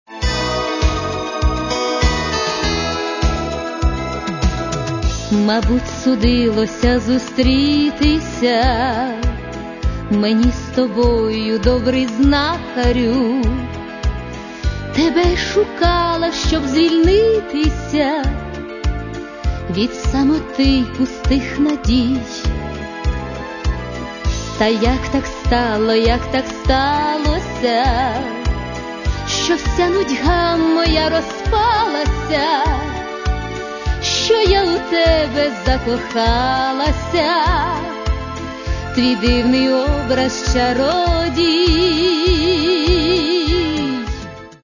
Каталог -> Эстрада -> Певицы